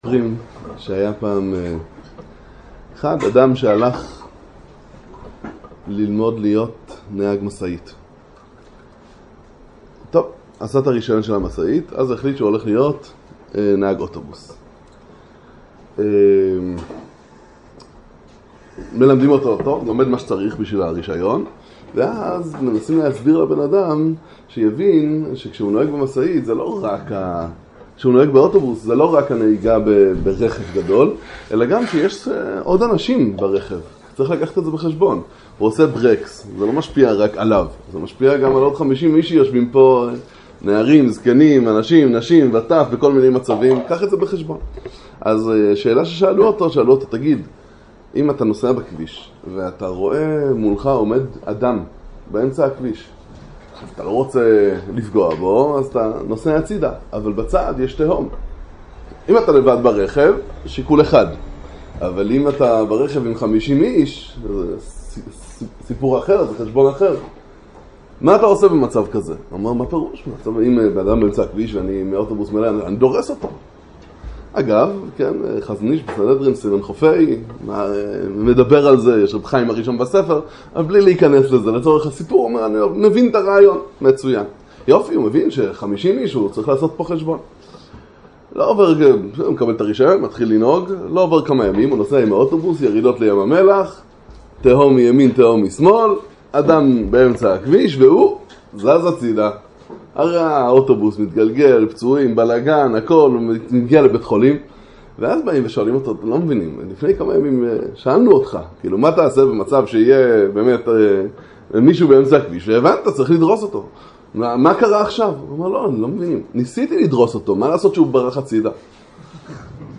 הרצאה בכוללל בעלי בתים, התמודדות עם תרבות המערב